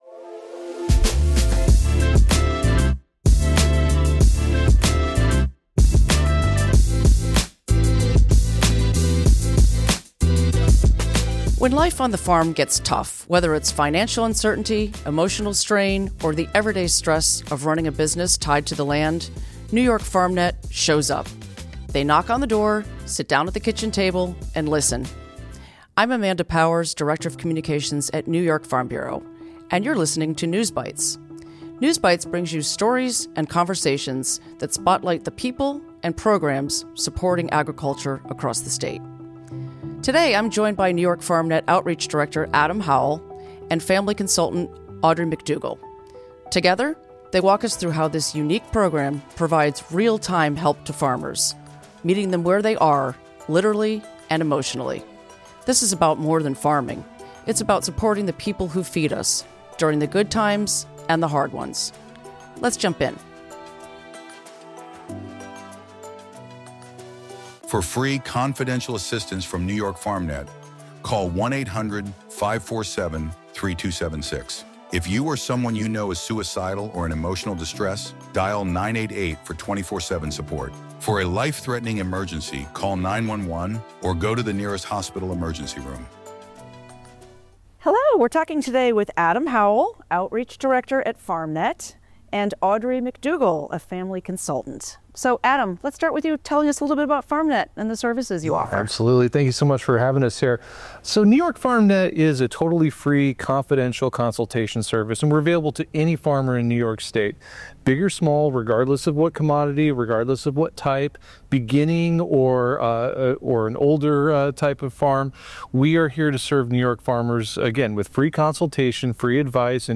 In this week's episode we hear directly from New York farmers on why the Farm Bill matters.